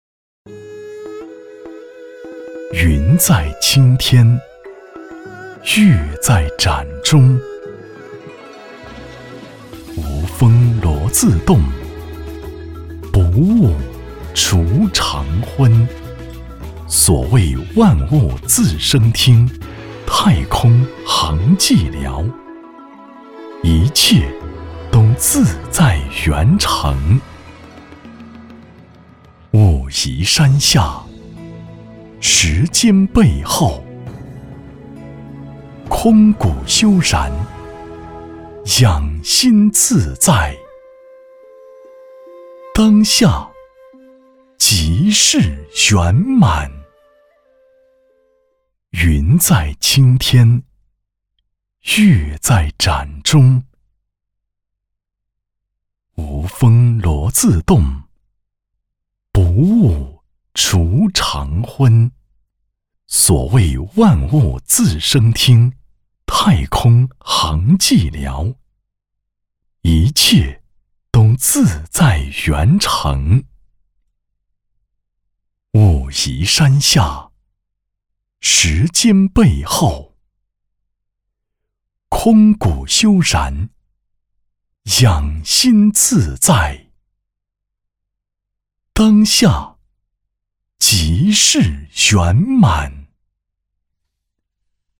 标签： 讲述
配音风格： 磁性 沉稳 讲述 浑厚